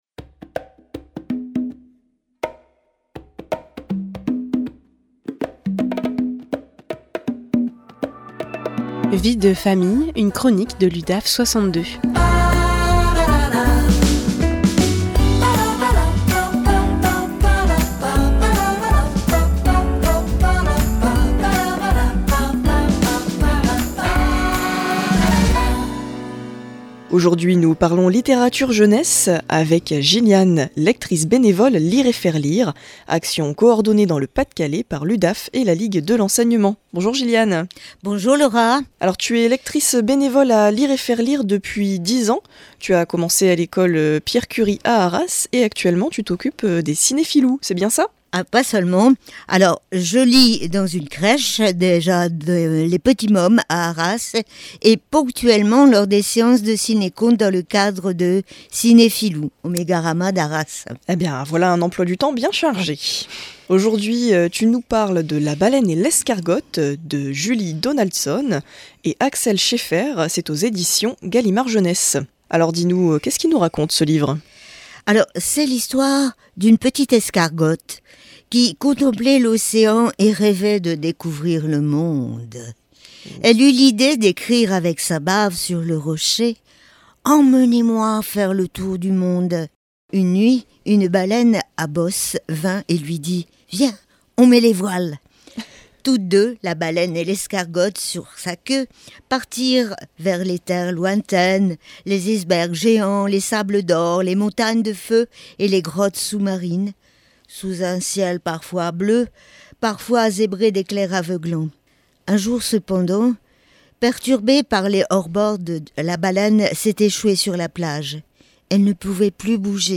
Vie de Famille, une chronique de l’Udaf62 en live sur RADIO PFM 99.9